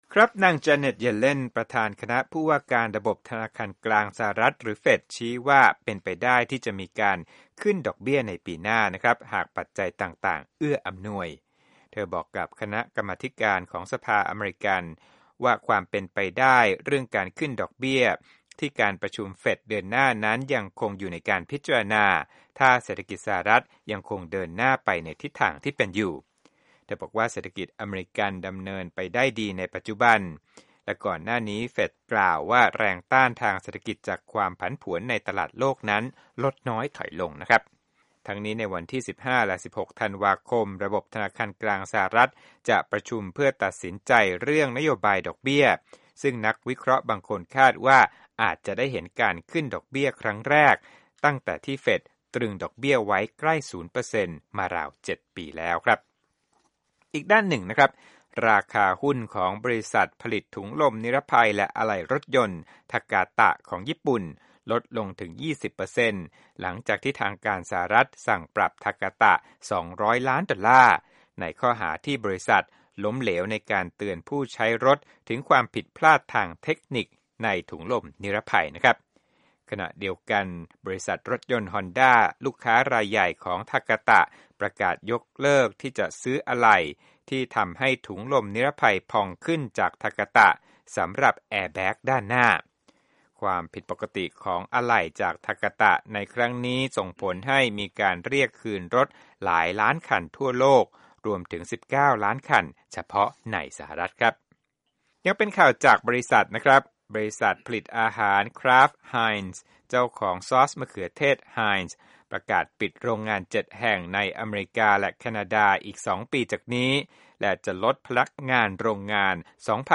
รวมข่าวธุรกิจ 11/04/2015